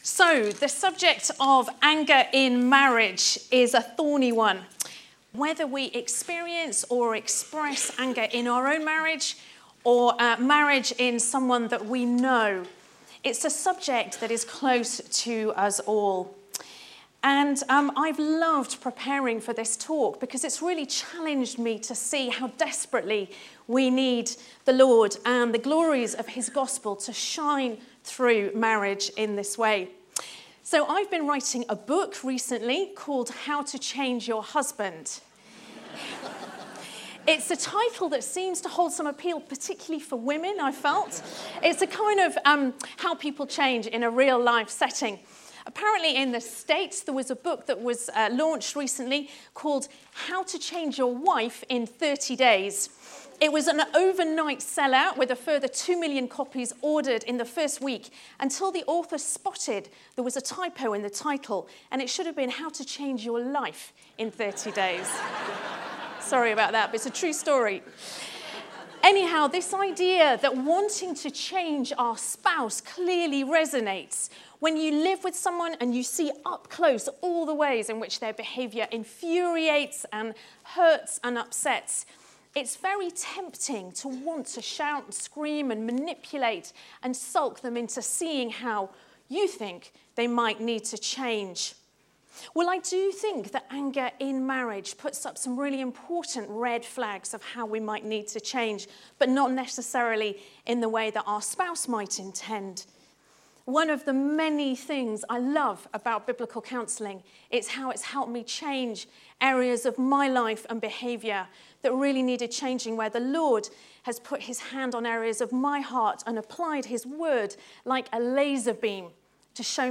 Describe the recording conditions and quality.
Anger: being cross, being ChristlikeResidential Conference, February 2018